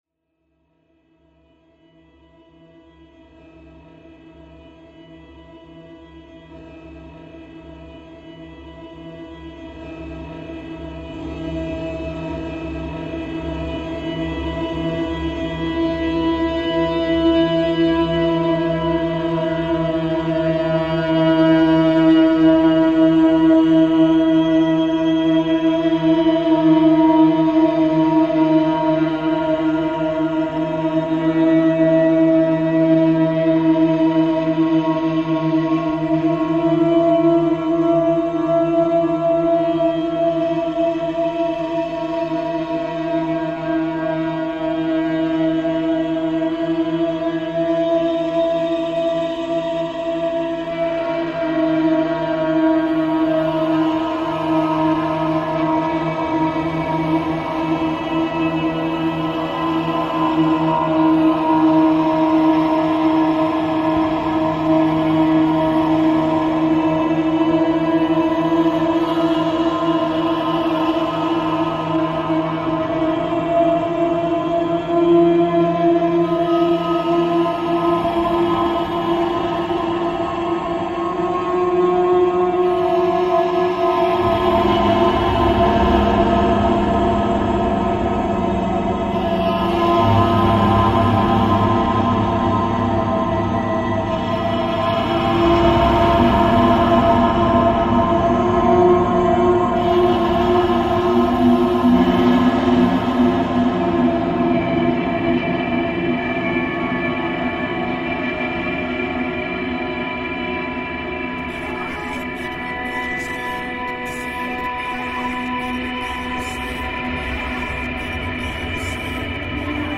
File under: Infected Ambient